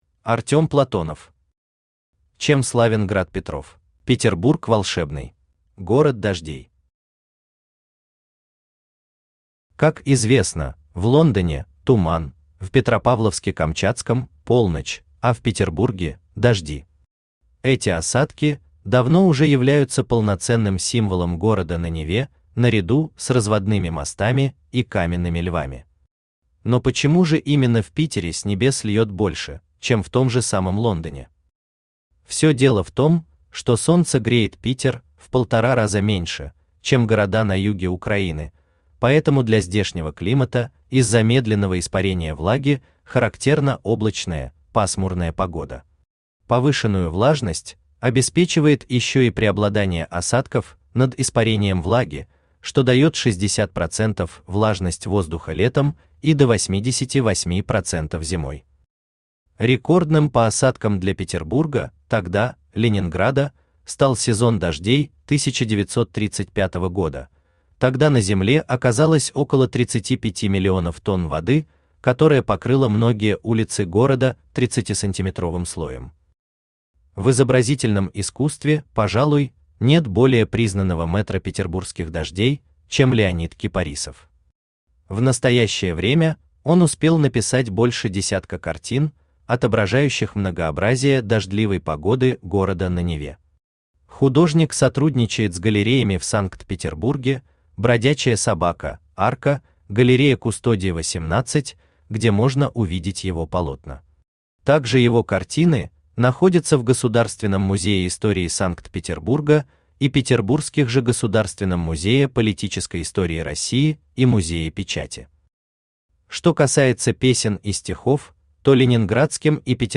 Аудиокнига Чем славен град Петров | Библиотека аудиокниг
Aудиокнига Чем славен град Петров Автор Артем Платонов Читает аудиокнигу Авточтец ЛитРес.